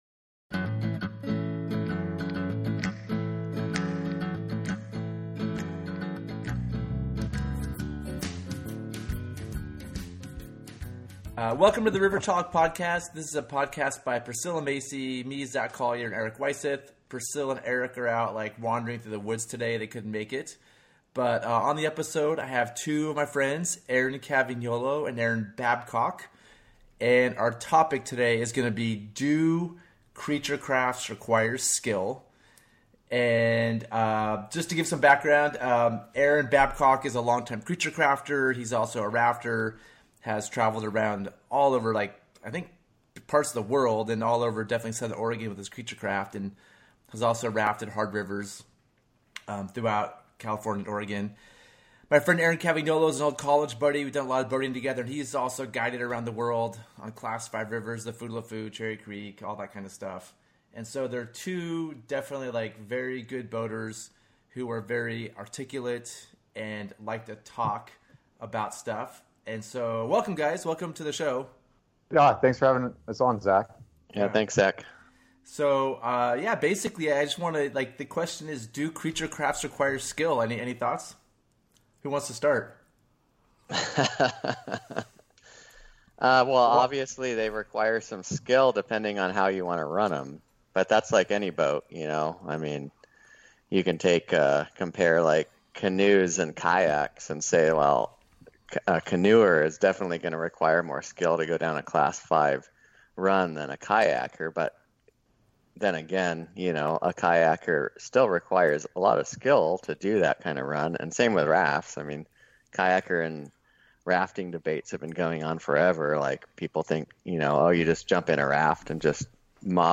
Gear Garage Live Show No. 53: Do Creature Crafts Require Skill?